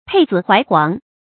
佩紫懷黃 注音： ㄆㄟˋ ㄗㄧˇ ㄏㄨㄞˊ ㄏㄨㄤˊ 讀音讀法： 意思解釋： 腰間佩掛紫色印綬，懷里揣著黃金官印。